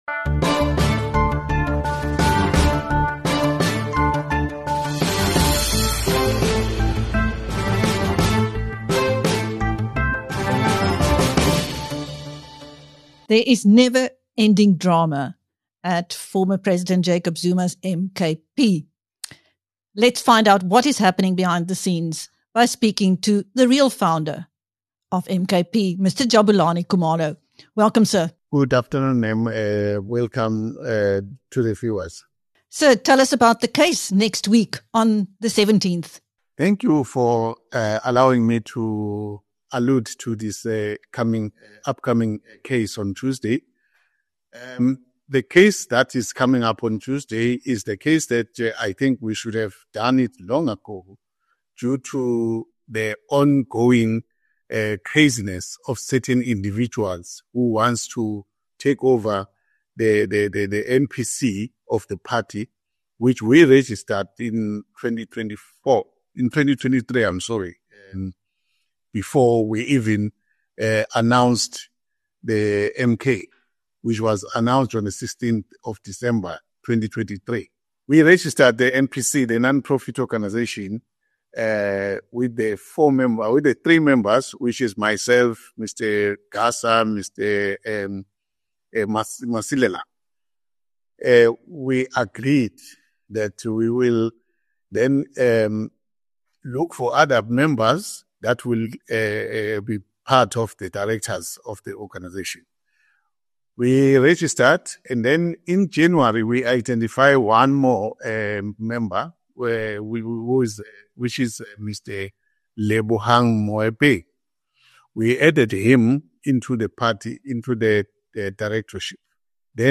Jabulani Khumalo, the real founder of MKP, headed by former President Jacob Zuma, is forging ahead with his legal battle to reclaim the party for its original leaders who had made huge sacrifices to get MKP off the ground and voted for by millions. In his latest interview with BizNews, Khumalo outlines the case that goes to court next week.